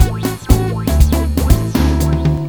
Samba 120-E.wav